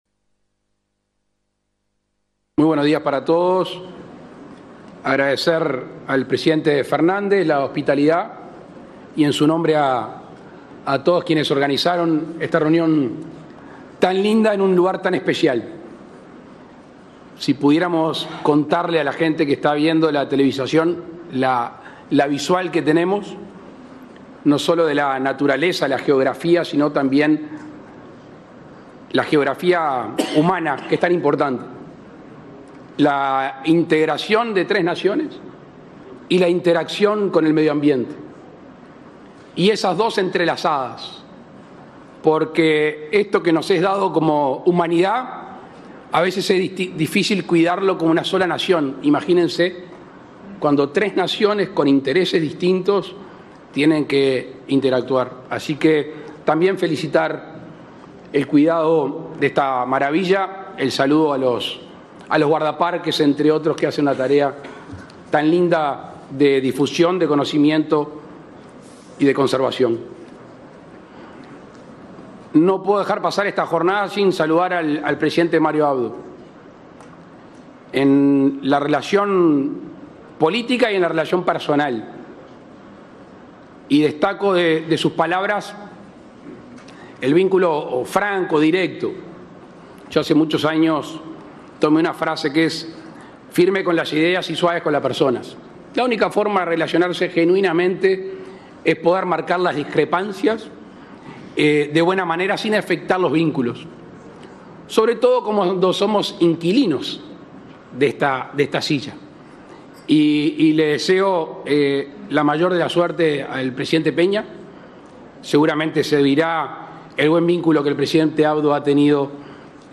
Intervención del presidente Luis Lacalle Pou
El presidente de la República, Luis Lacalle Pou, participó, este martes 4 en Argentina, en la Cumbre de Jefes y Jefas de Estado del Mercosur y Estados